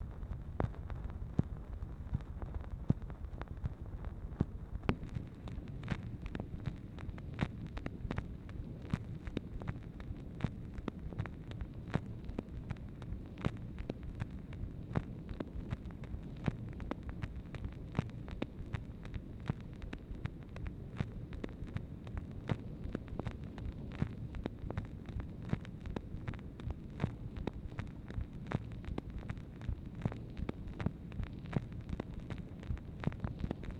MACHINE NOISE, March 30, 1964
Secret White House Tapes | Lyndon B. Johnson Presidency